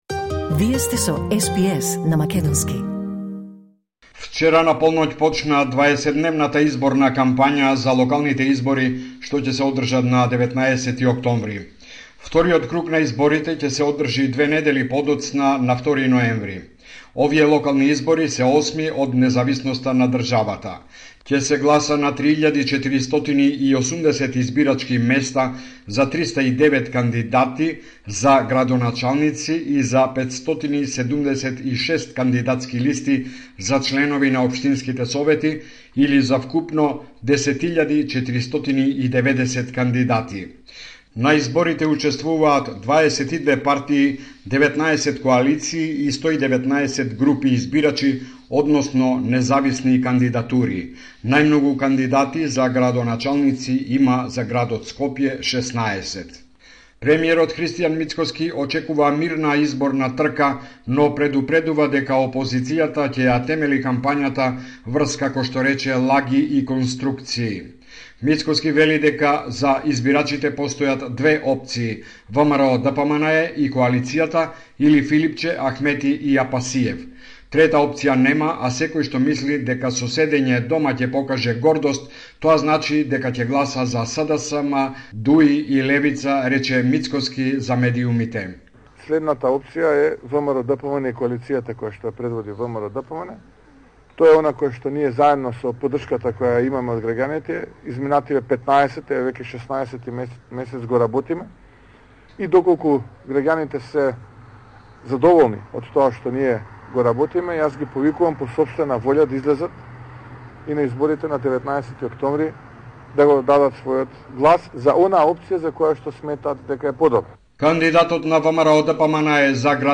Извештај од Македонија 30 септември 2025